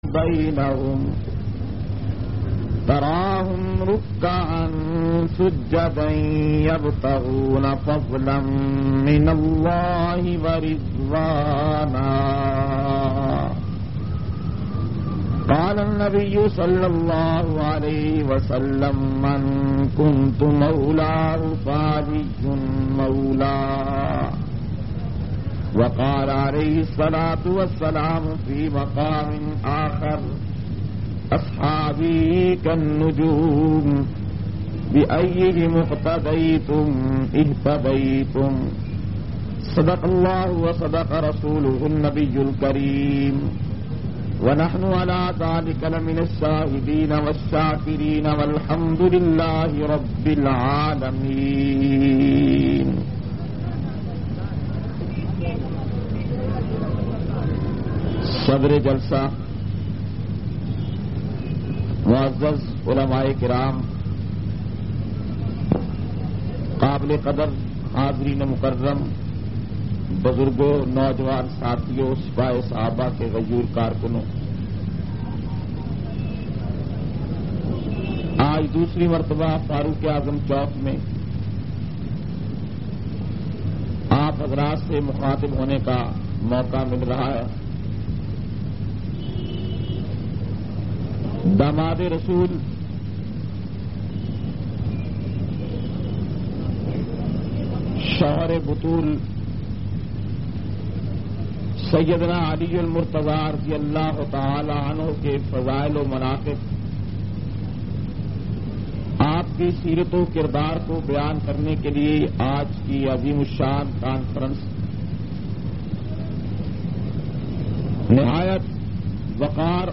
695- Seerat Hazrat Ali Conference-Charsaddah.mp3